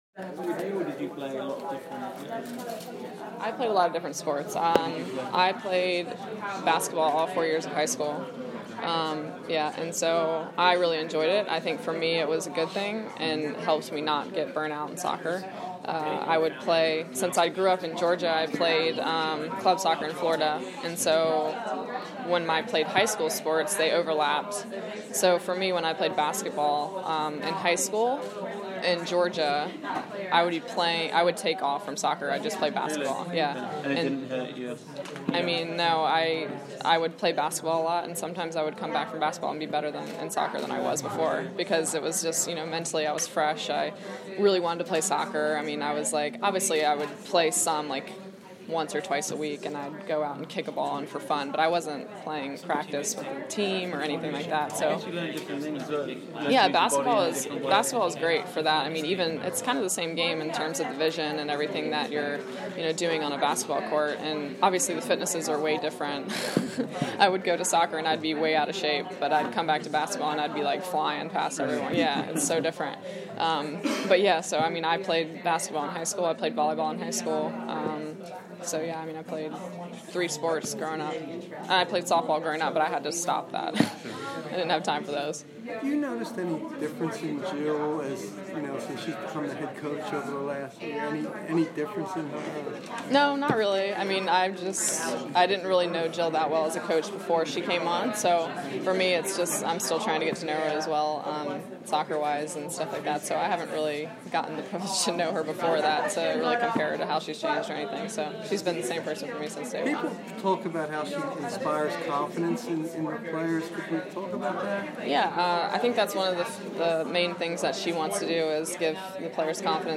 Morgan Brian roundtable